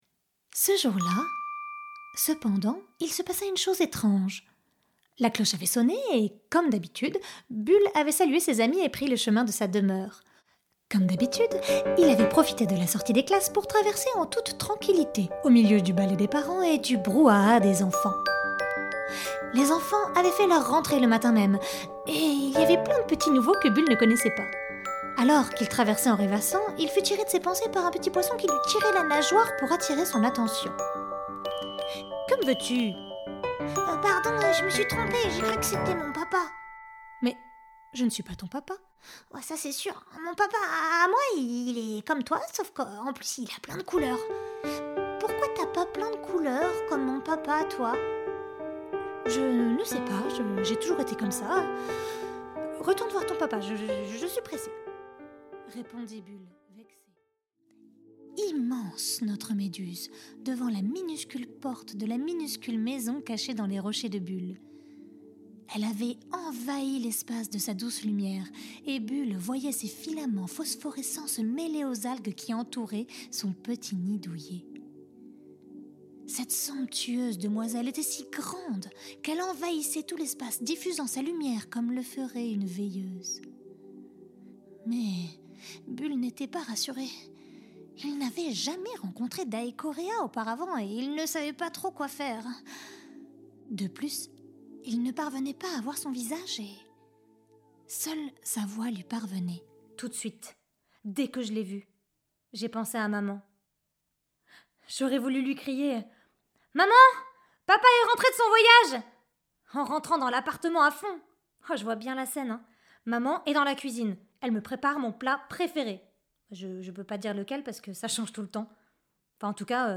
Démo voix